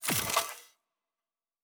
Weapon 14 Reload 3 (Flamethrower).wav